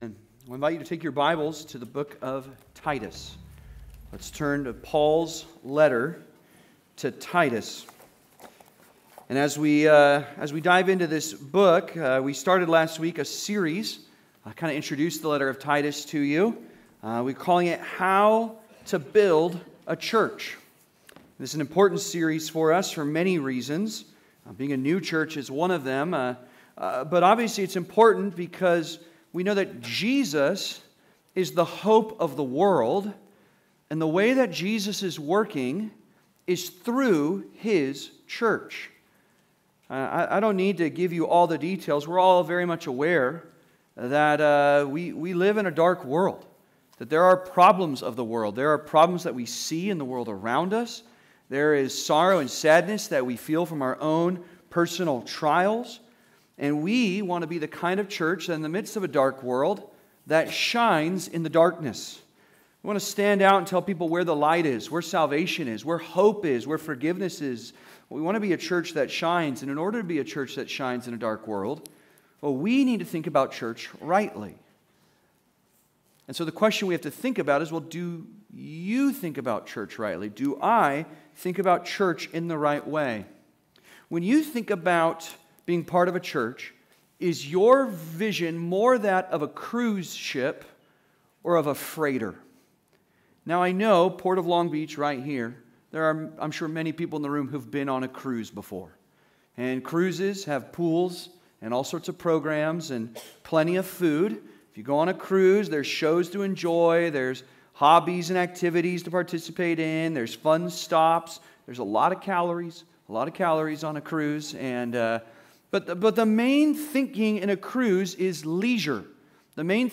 The Ministry Mindset (Sermon) - Compass Bible Church Long Beach